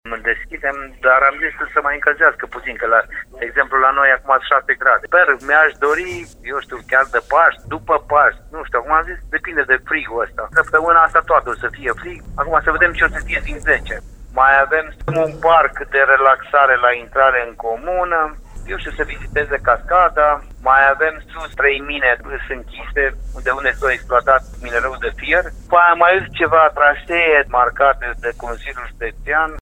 Nu este însă singura atracție a zonei, spune primarul comunei, Liviu Muntean.